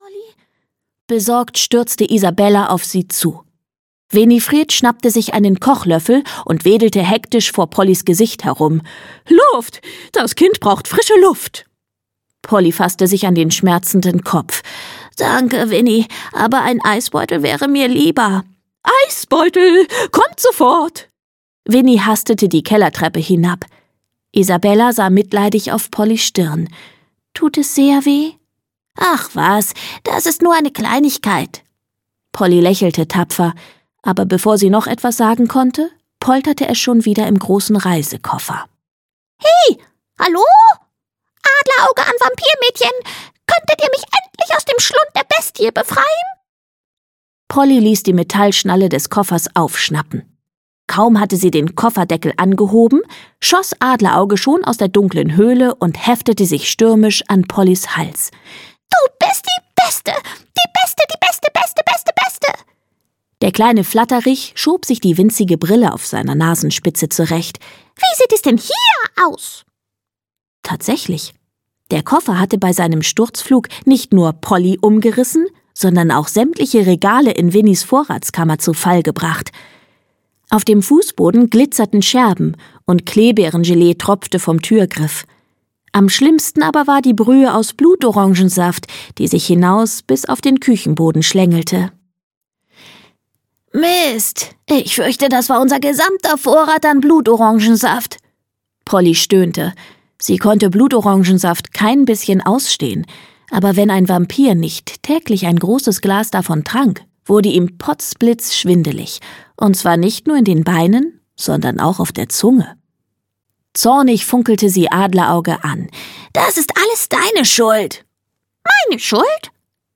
Polly Schlottermotz 3: Attacke Hühnerkacke - Lucy Astner - Hörbuch